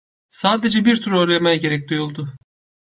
/ˈtuɾ/